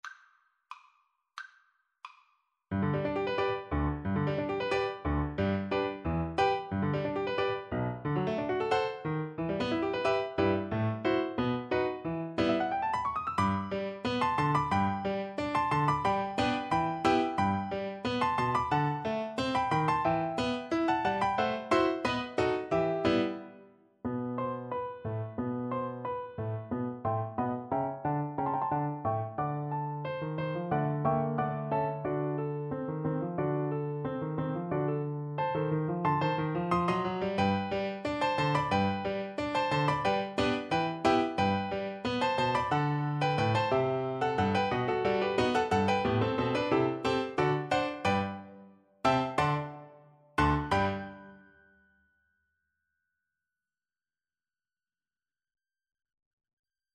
2/4 (View more 2/4 Music)
Classical (View more Classical Cello Music)